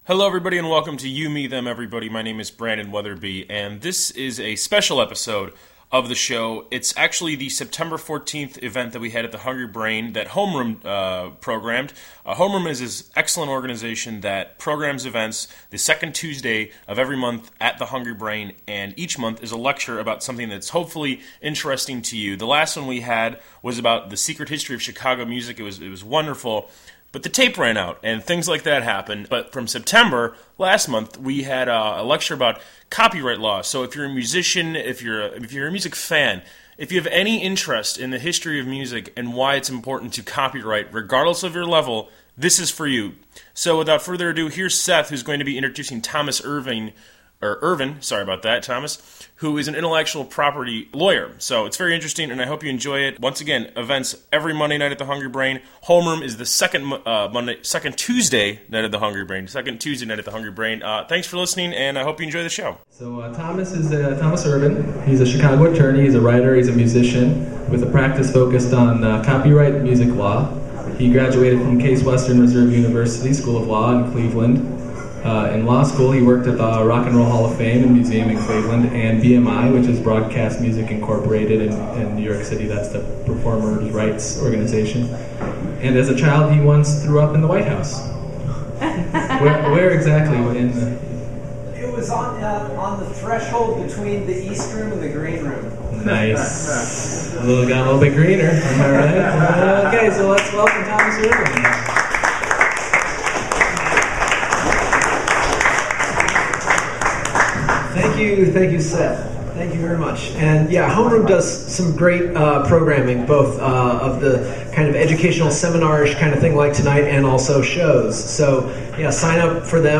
The second episode of a Homeroom lecture.